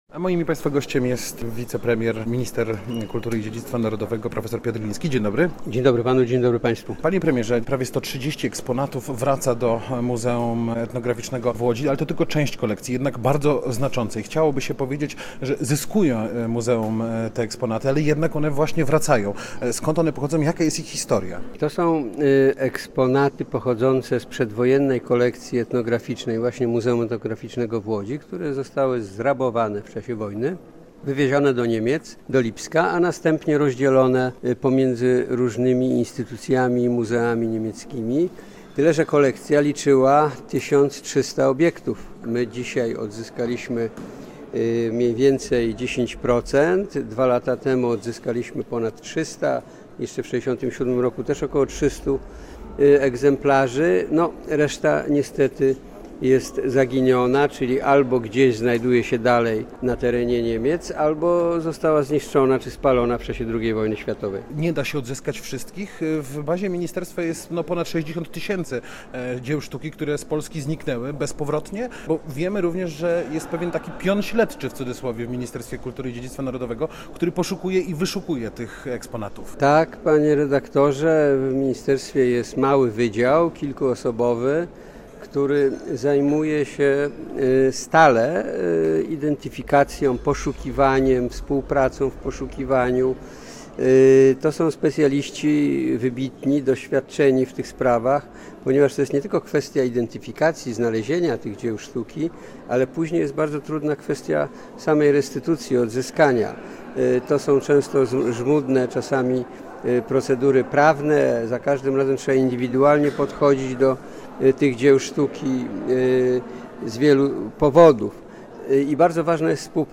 Posłuchaj: Nazwa Plik Autor Rozmowa z wicepremierem Piotrem Glińśkim audio (m4a) audio (oga) ZDJĘCIA, NAGRANIA WIDEO, WIĘCEJ INFORMACJI Z ŁODZI I REGIONU ZNAJDZIESZ W DZIALE “WIADOMOŚCI”.